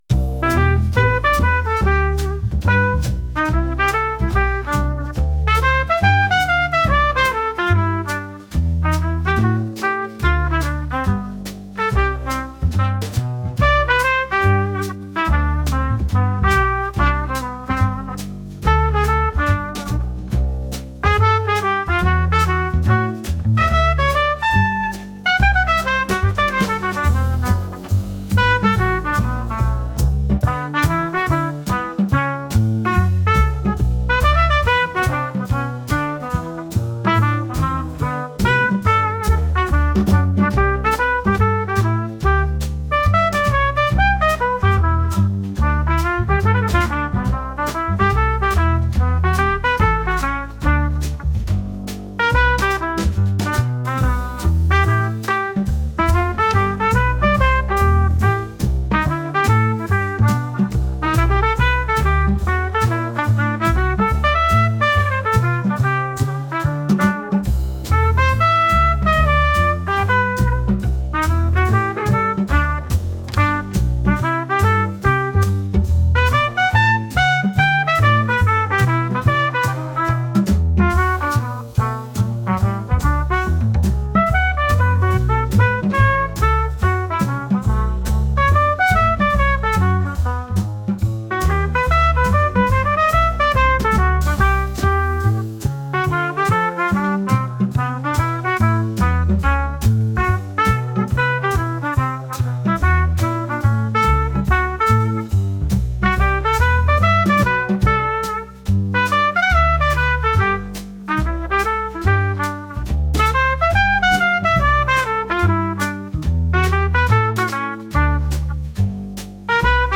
低音が気持ちいいジャズ音楽です。